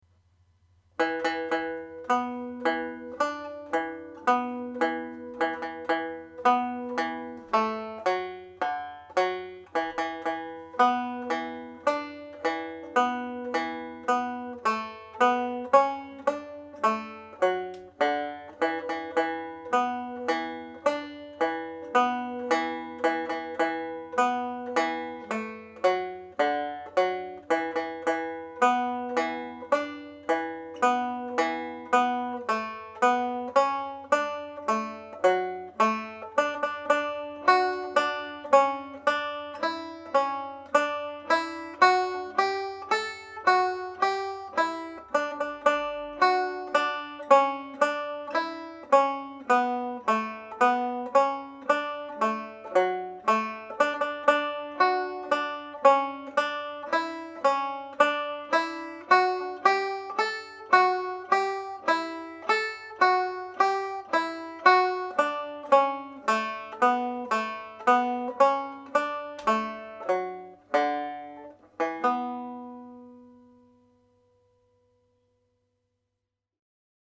Reel (E Minor)
Played slowly
Drowsy-Maggie_Slow.mp3